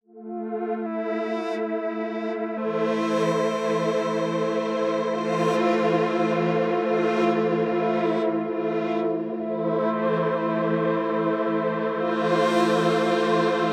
Cloakaine_Pad.wav